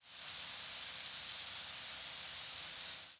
Seaside Sparrow diurnal flight calls
Fig.2. New Jersey August 6, 1996 (MO).
Perched bird.